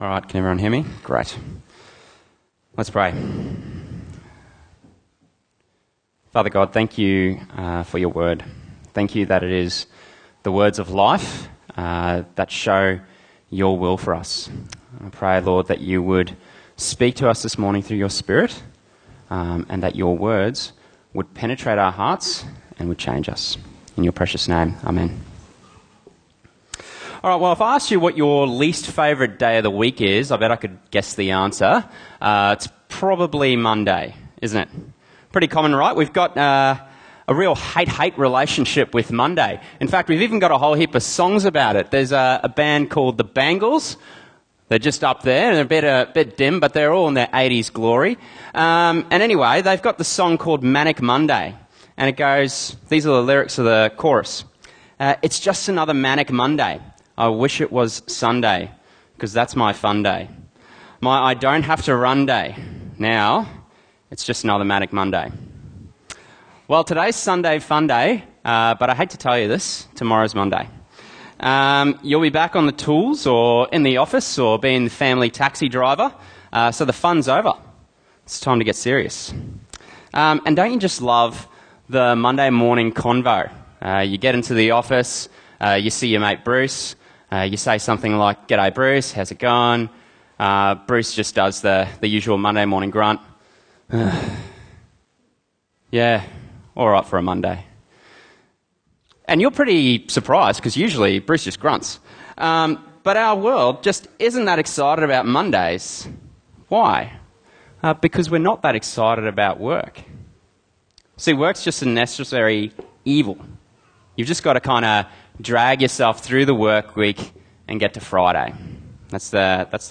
Bible Talks Bible Reading: Ephesians 6:5-9